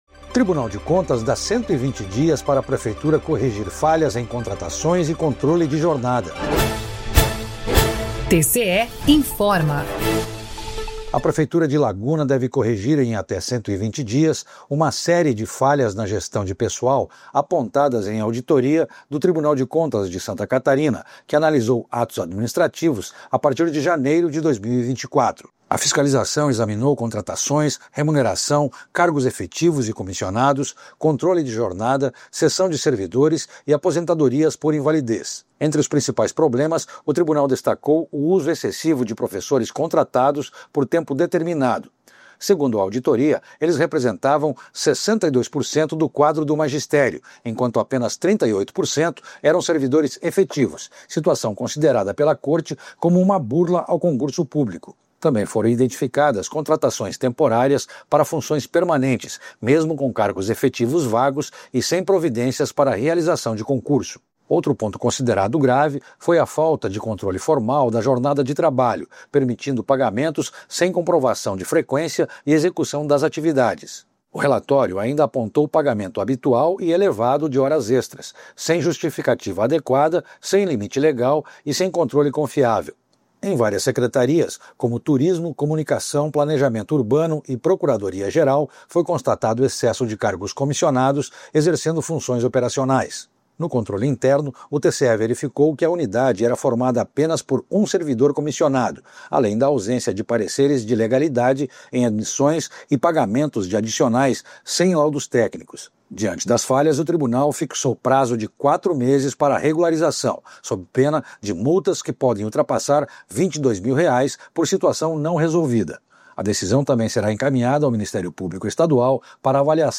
VINHETA TCE INFORMA
VINHETA TCE INFORMOU